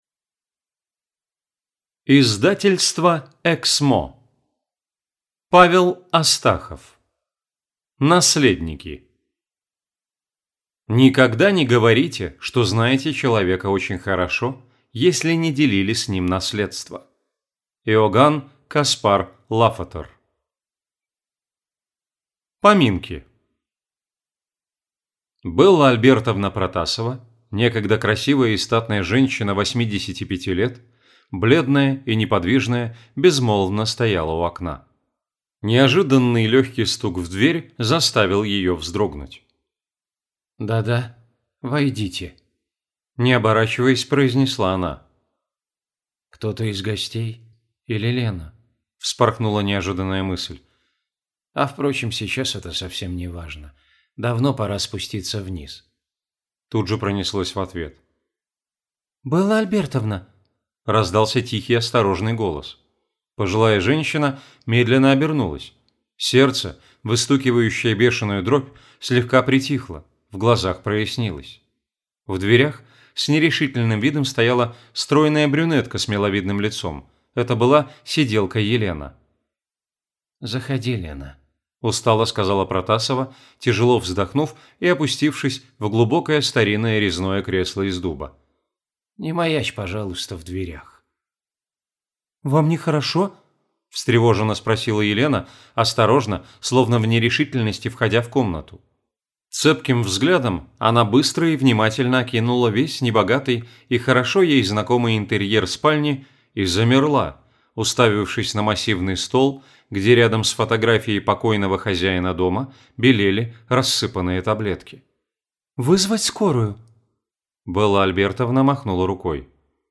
Аудиокнига Наследники | Библиотека аудиокниг
Читает аудиокнигу